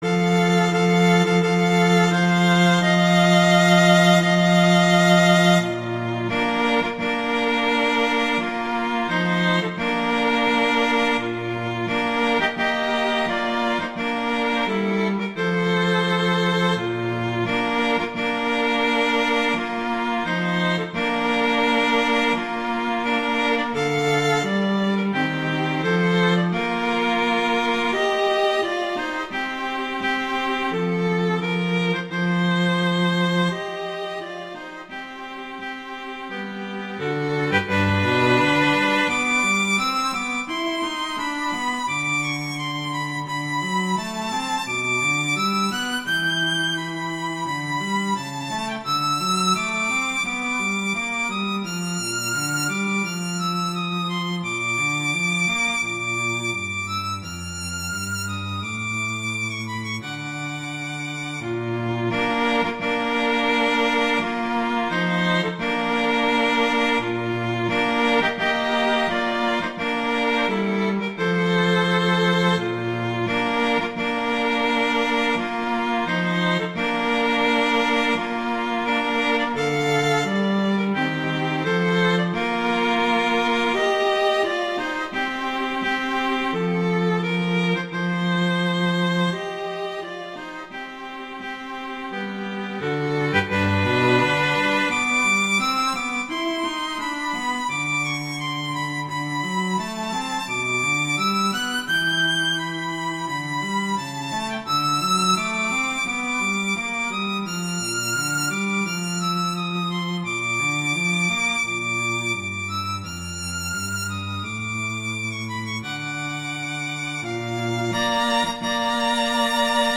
Instrumentation: violin & cello
arrangements for violin and cello
wedding, traditional, classical, festival, love, french